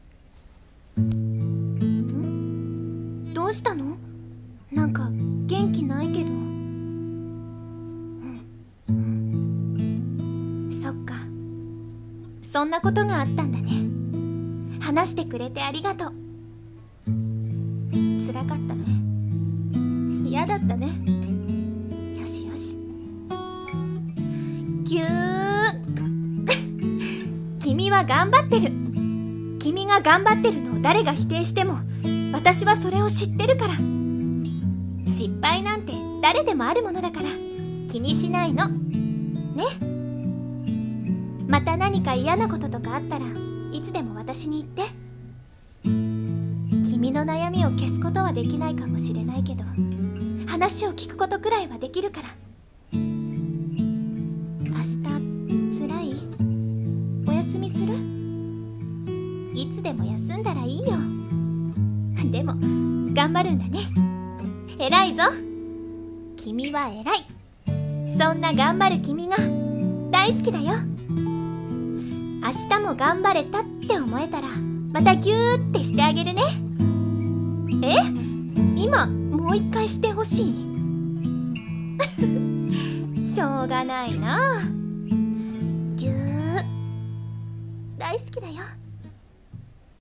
【声真似】癒し